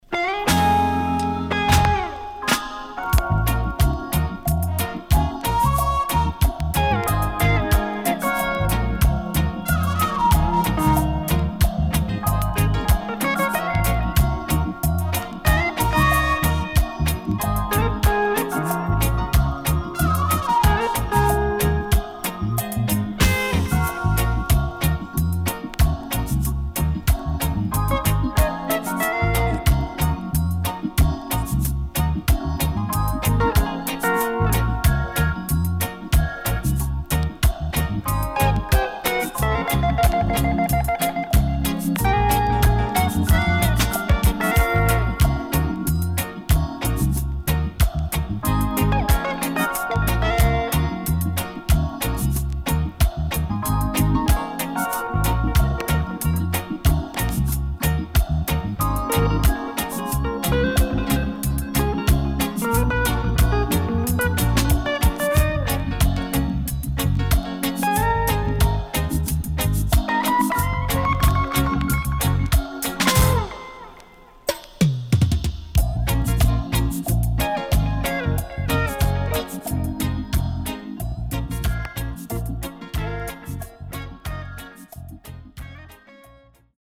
CONDITION SIDE A:VG+
【12inch】
SIDE A:少しチリノイズ入ります。